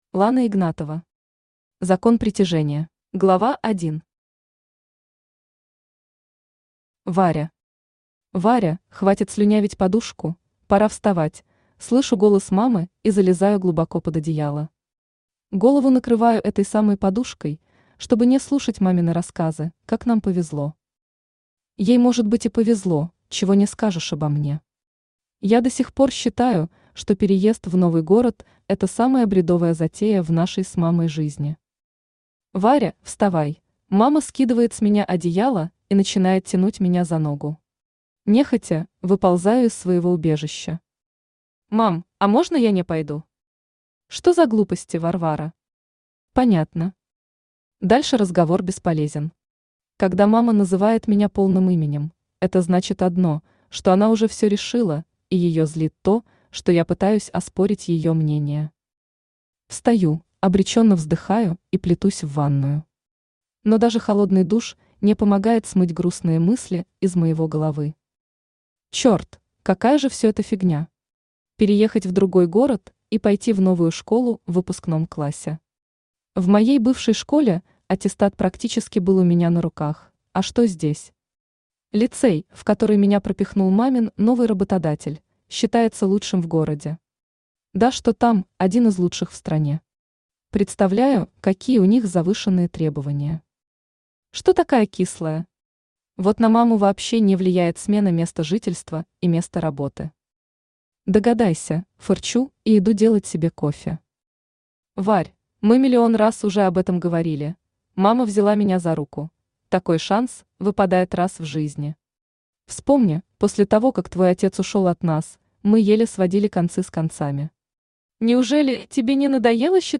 Аудиокнига Закон притяжения | Библиотека аудиокниг
Aудиокнига Закон притяжения Автор Лана Александровна Игнатова Читает аудиокнигу Авточтец ЛитРес.